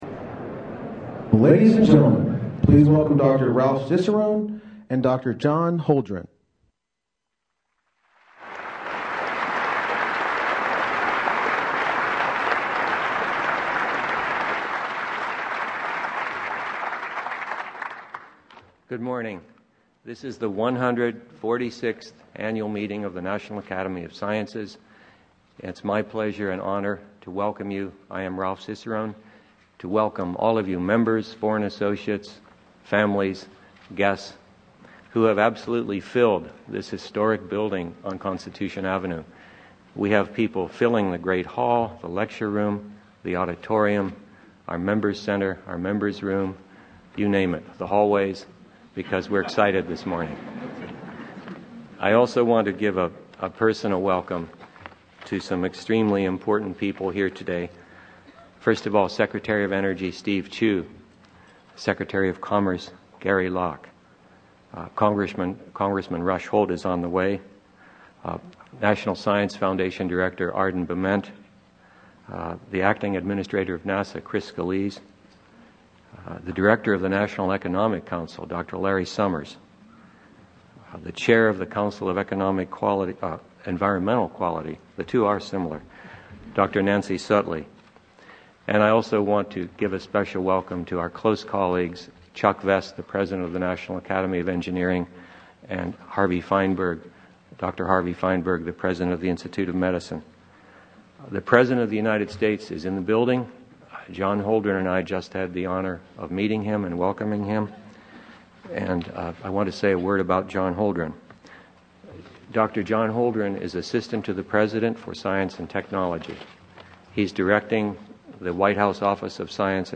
U.S. President Barack Obama speaks at the annual meeting of the National Academy of Sciences